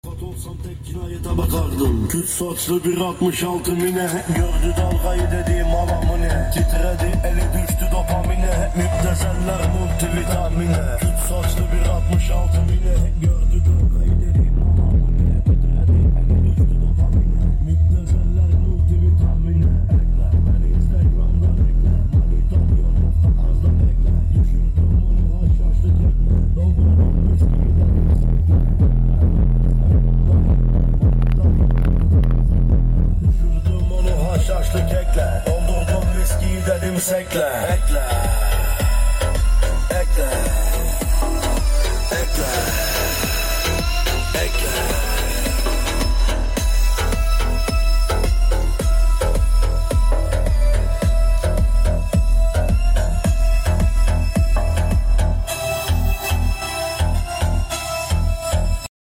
BMW E63 650i 2008 facelift sound effects free download
Mtx subwofer 15 inç 2000w rms 6000w Max